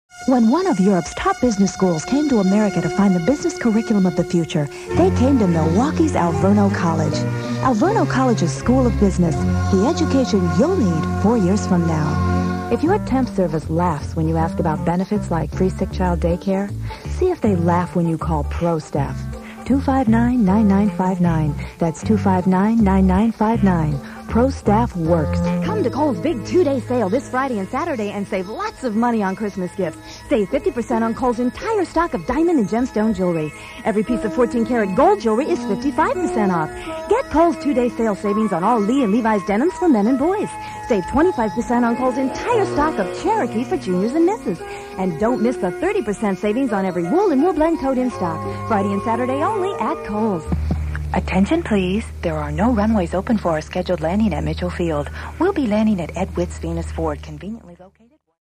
Female Voice Talent